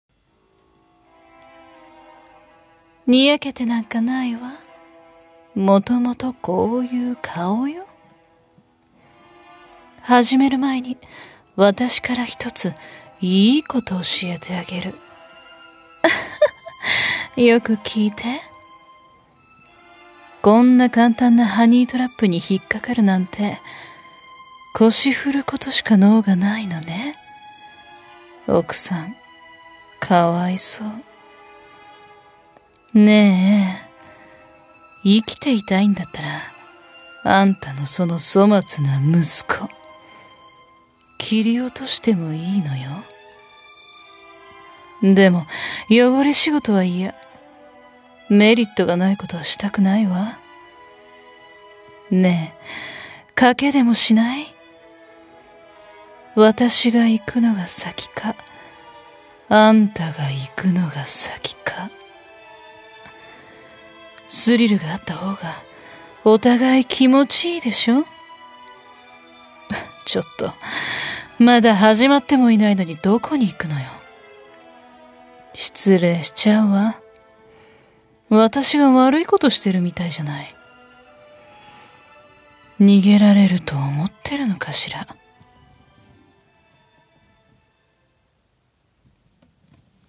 【声劇】賭けでもしない？